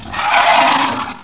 Imp